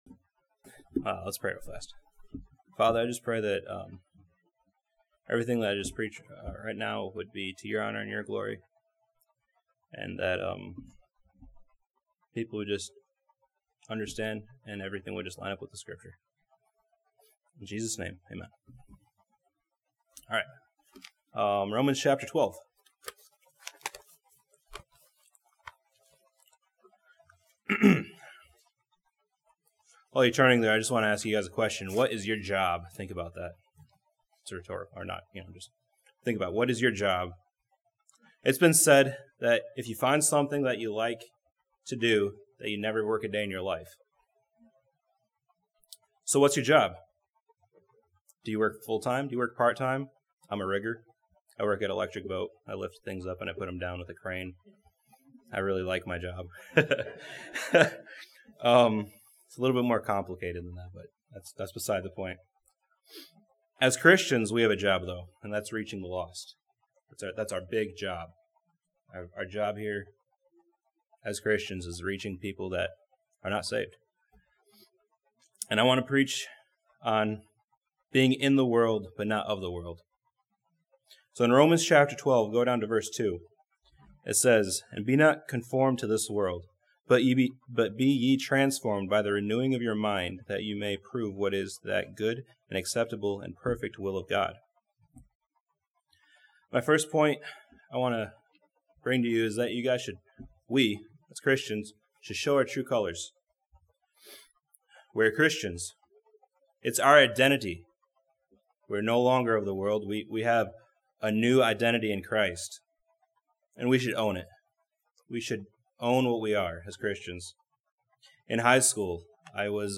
This sermon from Romans chapter 12 challenges believers to preach the Gospel to those around them and not be ashamed.